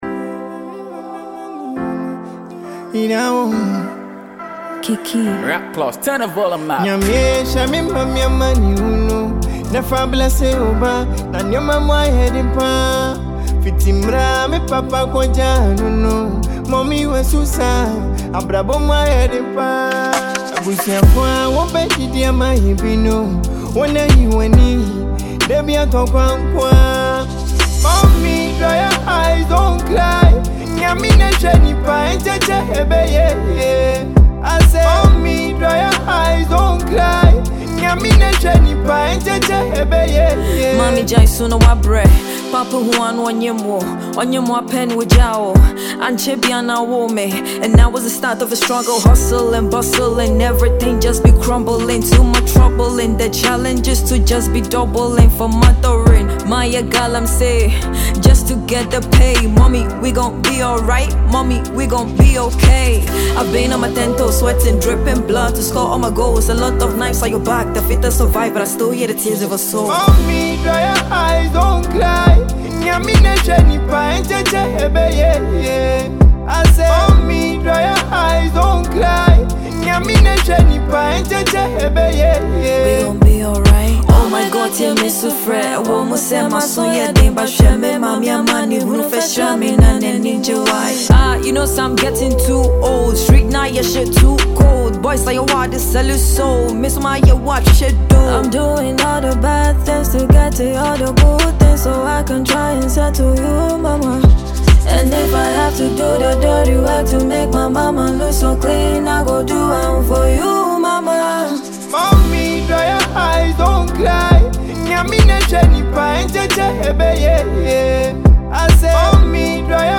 a Ghanaian female artist